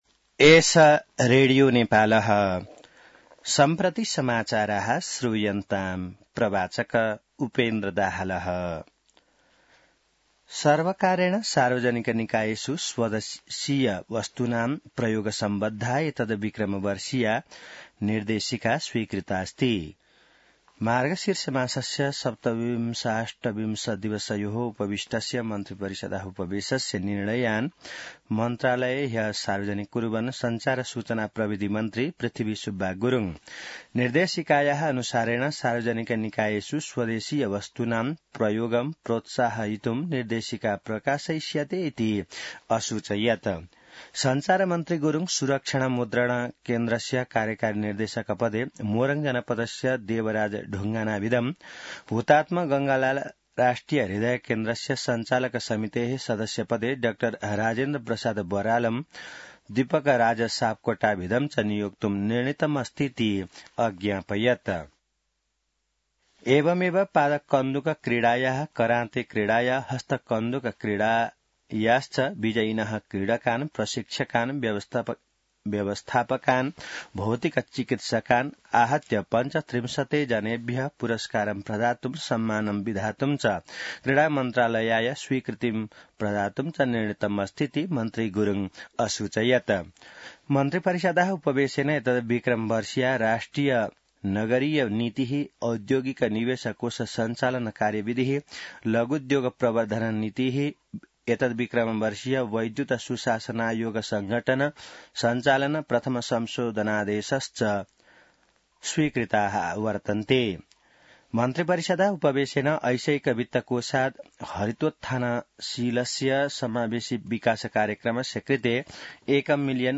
संस्कृत समाचार : ३ पुष , २०८१